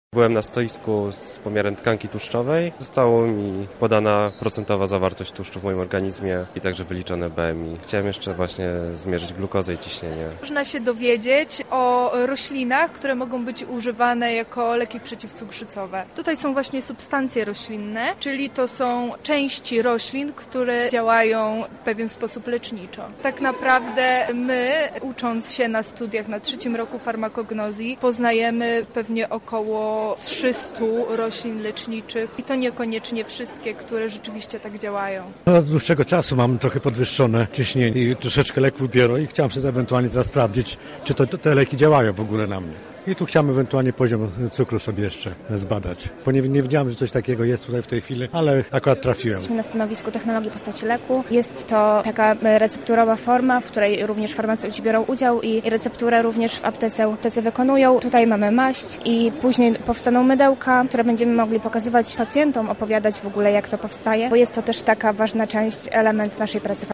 Na miejscu była również nasza reporterka.